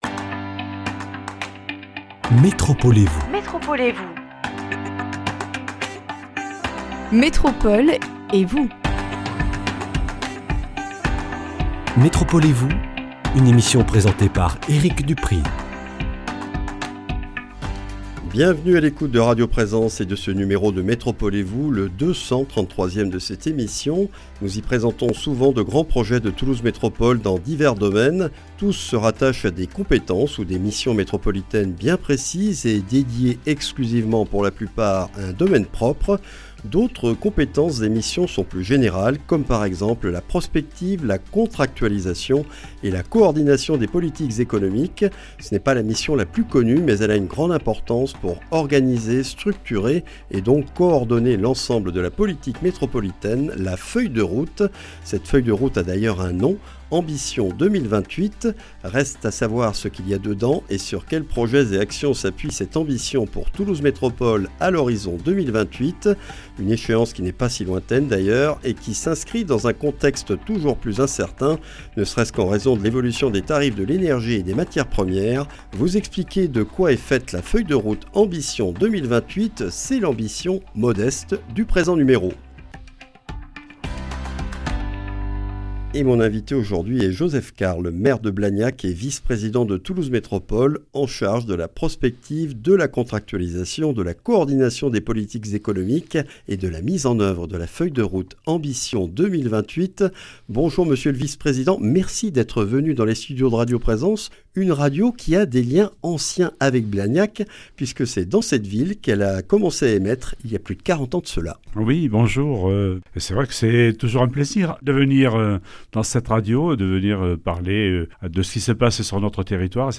Ambition 2028 est le nom donné à la feuille de route économique de Toulouse Métropole. Un document structuré autour de 7 projets et de 23 actions que nous présente Joseph Carles, maire de Blagnac, vice-président de Toulouse Métropole en charge de la Prospective, de la Contractualisation, de la Coordination des politiques économiques et de la mise en oeuvre de la feuille de route Ambition 2028.